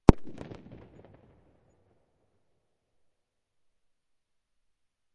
奇异世界的反向爆炸
描述：这个声音是通过爆开一个玻璃纸袋做成的，用MAudio Microtrack II录制。这个声音作为烟花或爆炸声听起来非常好，特别是有混响。
标签： 爆炸 爆炸 烟花 响亮 流行 相反
声道立体声